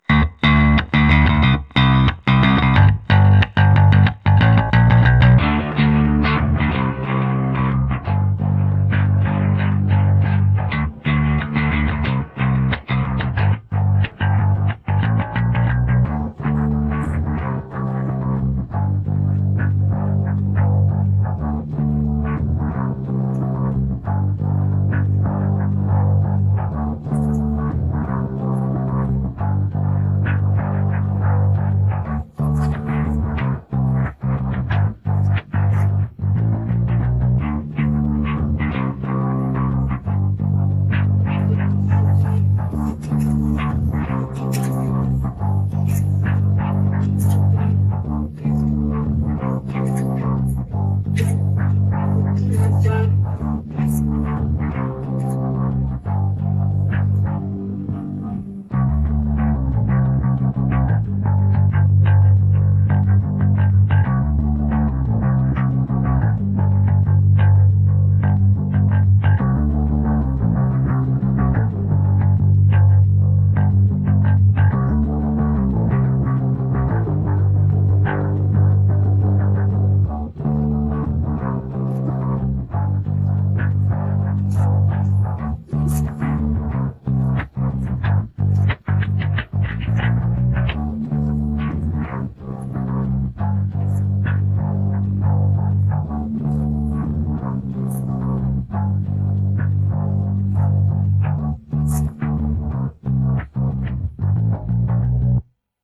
The bass separation is far from what the bass would sound like in the original multi-track recording, but it contains most of the bass and only a small amount of the other instruments:
Bass
879-Audacity-Separation-Bass.mp3